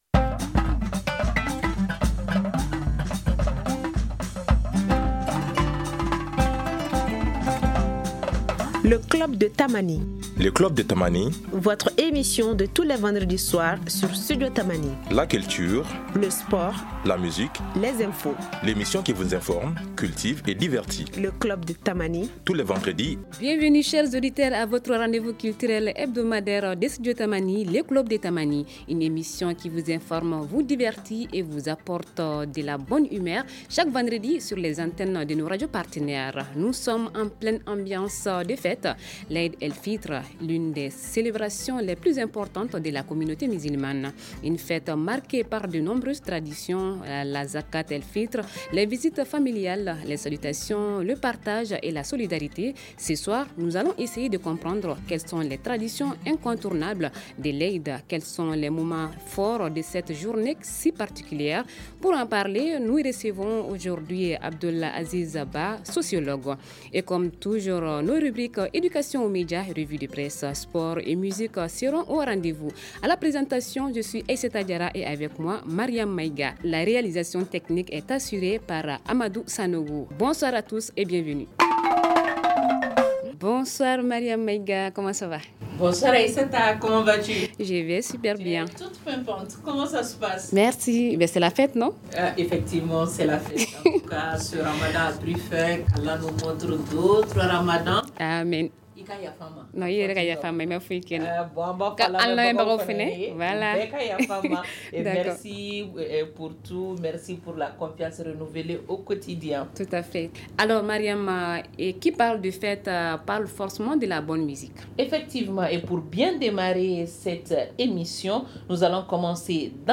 Avec notre invité